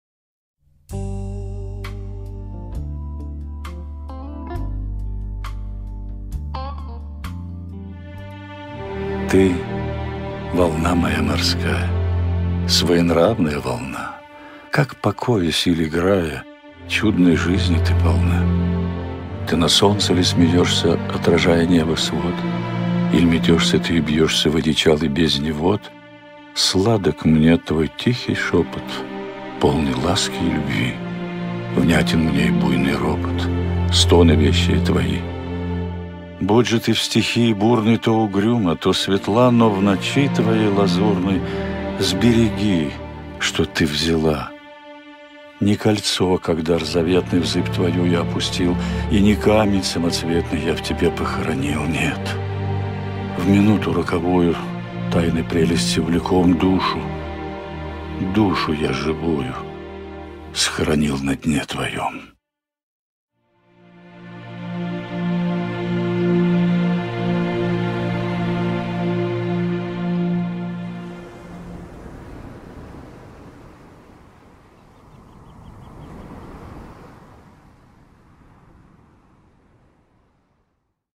Tyutchev-Ty-volna-moya-morskaya-Chitaet-Leonid-Kulagin-stih-club-ru.mp3